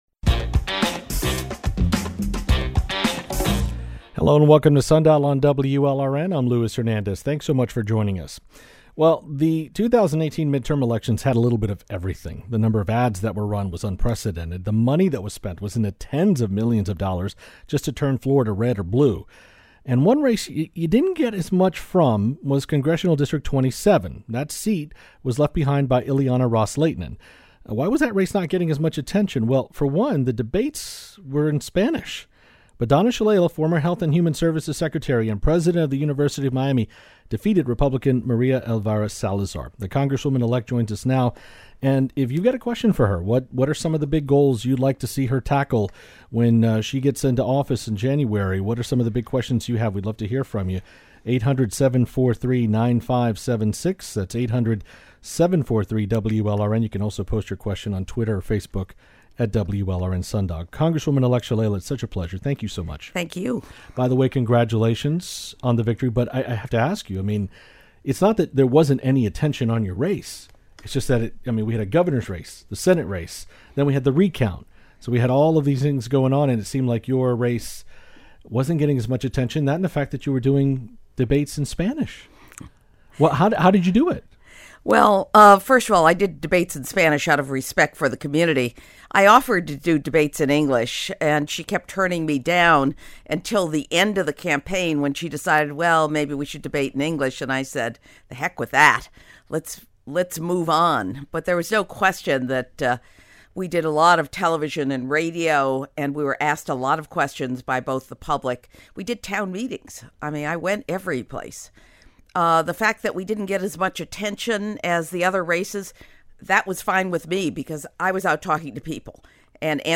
Listen to our full interview with Congresswoman-elect Donna Shalala.